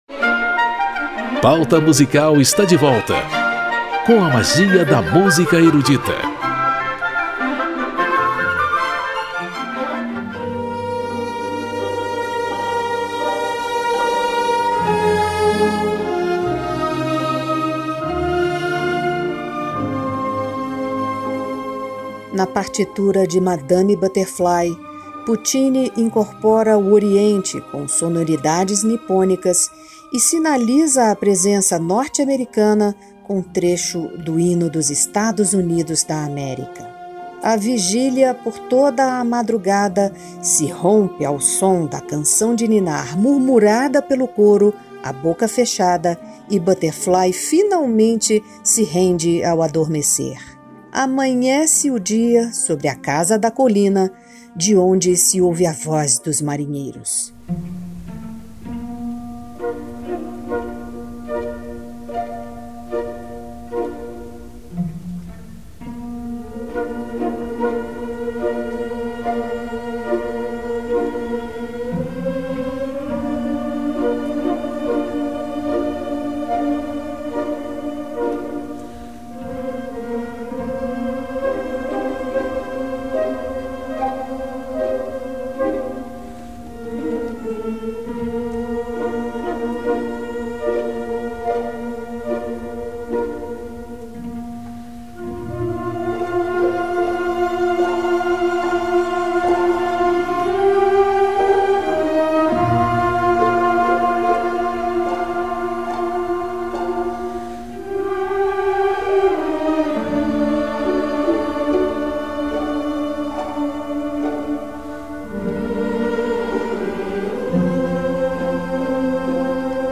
A conclusão de uma história passada em Nagasaki que revela faces da honra e da vergonha na cultura do oriente, através do ritual suicida japonês. Solistas de renome internacional, Coro e Orchestra del Teatro dell'Opera di Roma, sob a regência do maestro Sir John Barbirolli, interpretam a ópera Madame Butterfly, de Giacomo Puccini.